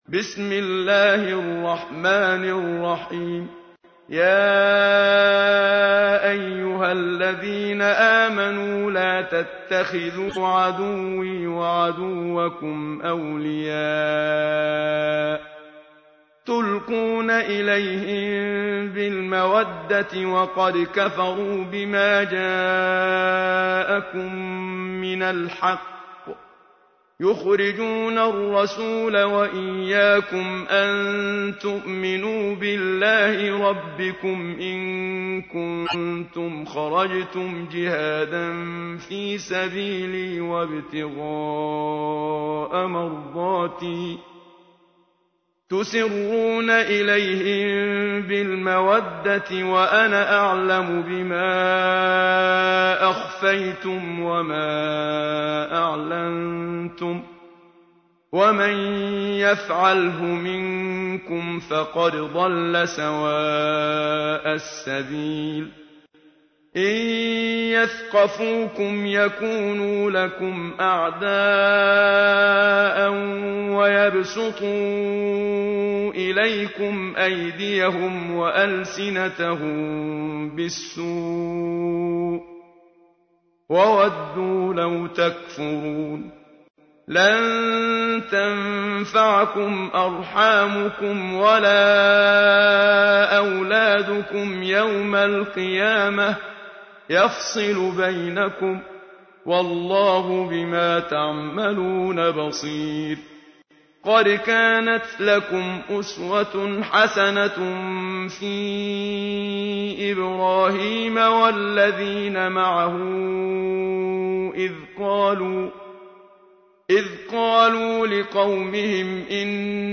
سوره ای که با خواندنش از بیماری ها محفوظ خواهید ماند+متن و ترجمه+ترتیل استاد منشاوی